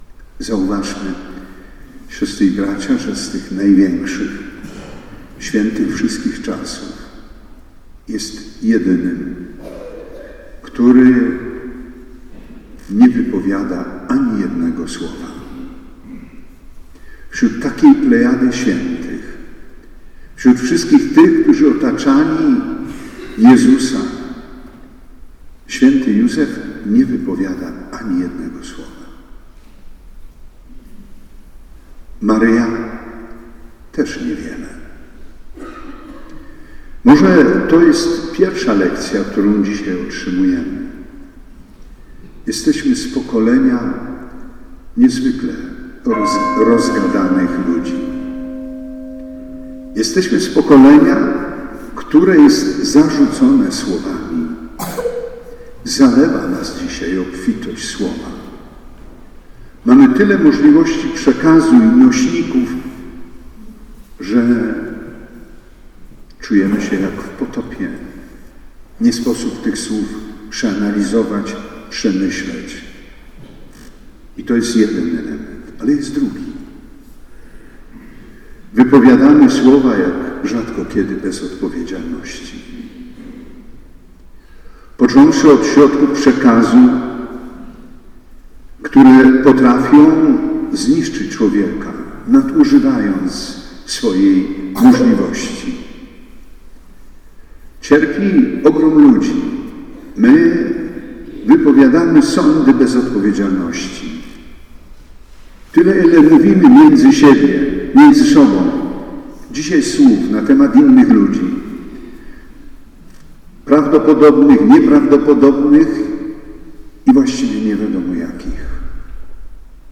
Ordynariusz diecezji 19 marca przewodniczył uroczystej Mszy św. w sanktuarium św. Józefa w Słupsku.
Zapraszamy do wysłuchania obszernych fragmentów homilii.